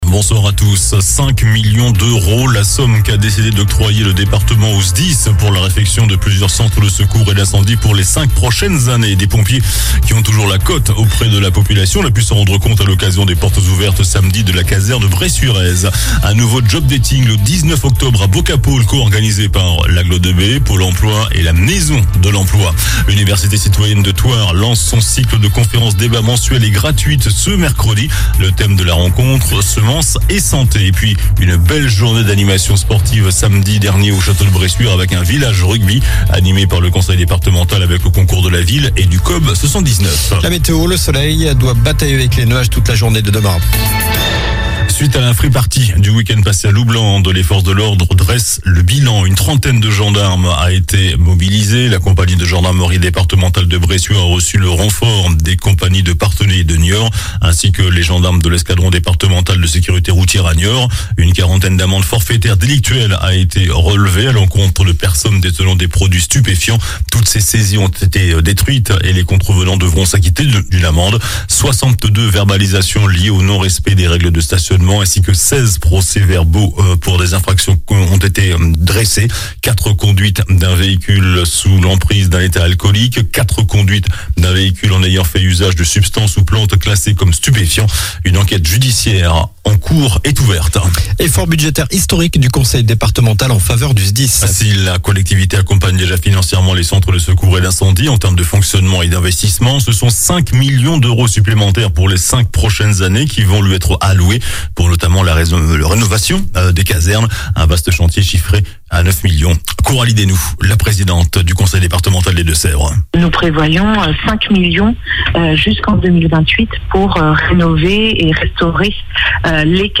JOURNAL DU LUNDI 25 SEPTEMBRE ( SOIR )